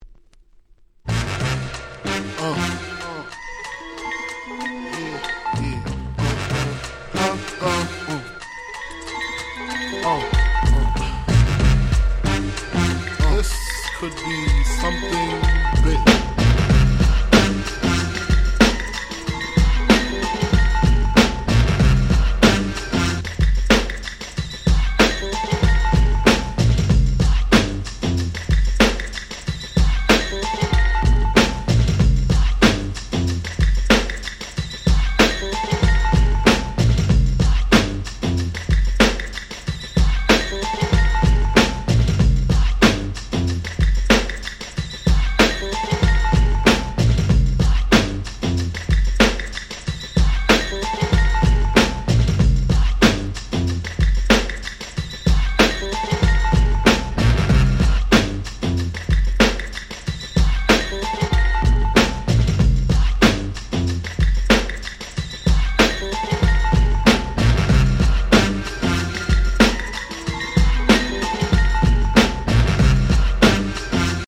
95' Super Nice 90's Hip Hop !!